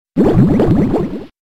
bubbleunderwater.wav